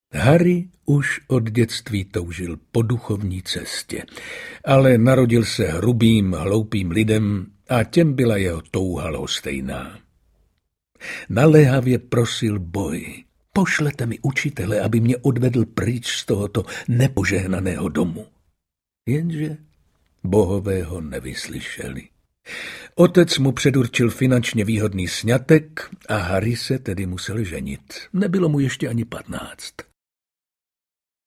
Duhová jiskra audiokniha